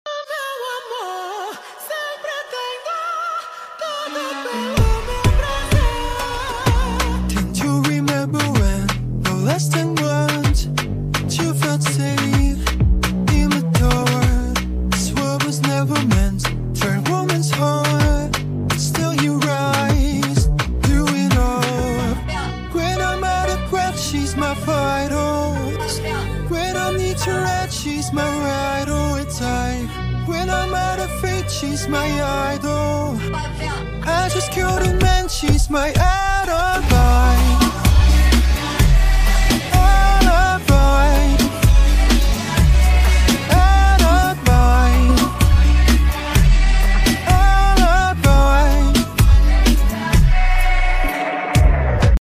Utilice mí propio modelo de voz para hacer este cover